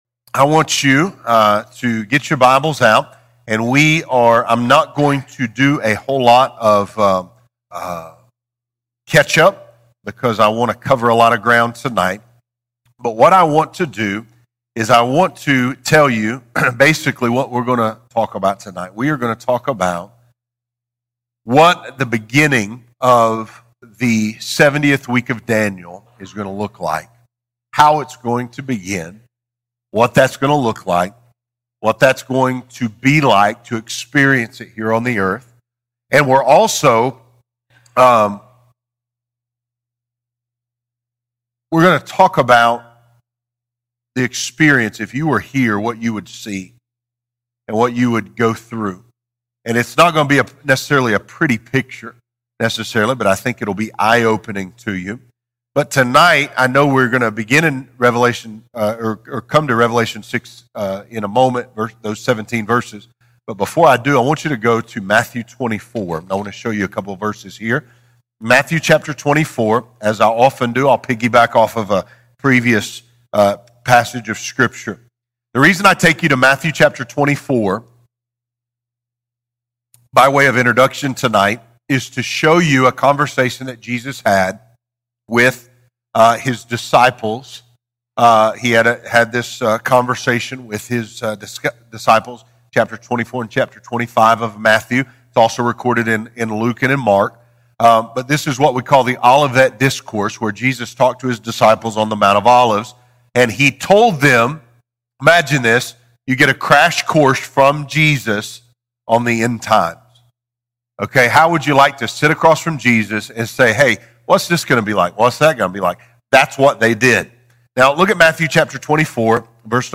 Have you ever wondered what the book of Revelation really means and how it relates to the current times? This is a verse-by-verse, bite-by-bite, in-depth Bible study that does not speculate or guess; letting Scripture speak and authenticate this important, but often overlooked book in the Bible.